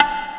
soft-hitnormal.wav